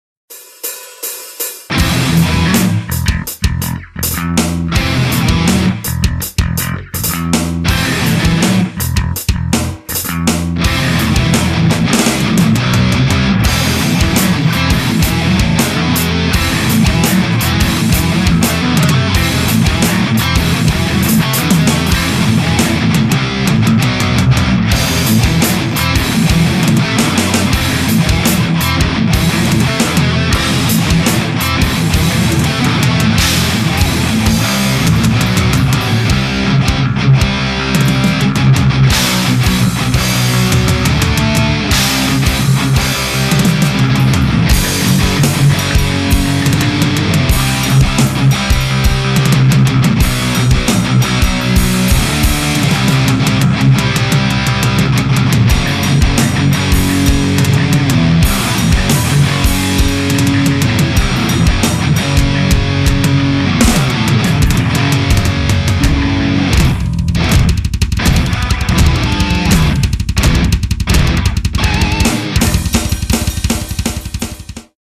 Classic, Metal, Rock Progresywny, Gitara, Perkusja, Bas